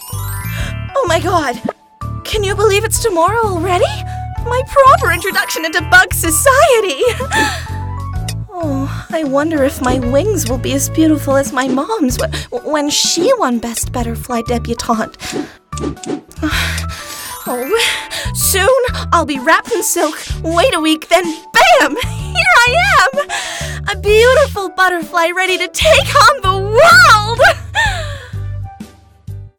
Her sound is very easy on the Ear. She also has an ability to cover a very wide age range and creates fantastically full and rich characters completely different from one to the other perfect for Gaming and Animation.